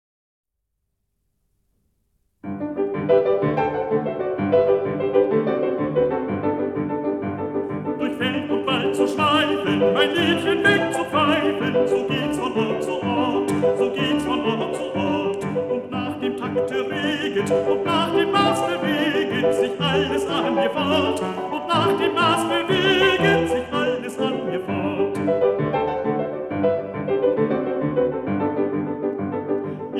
Solo Instrumentals